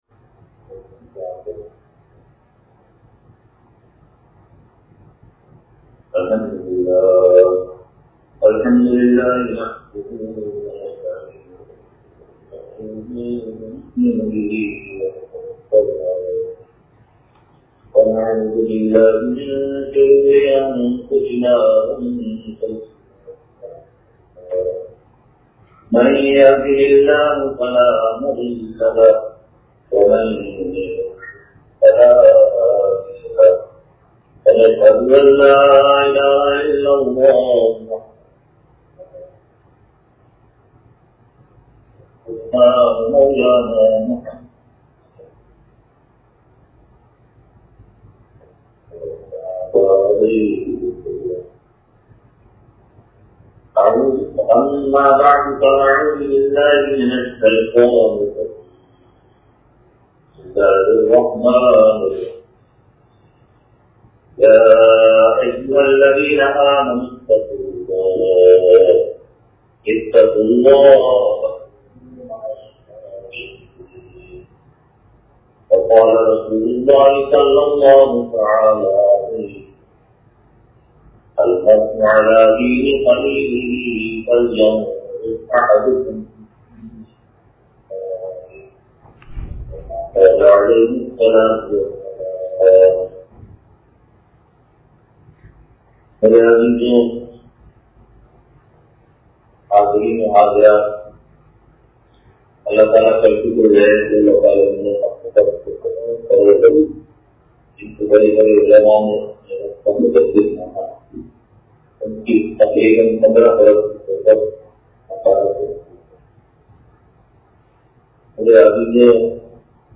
حضرت والا دامت برکاتہم کا اسلام آباد سے براہ راست بیان – اتوار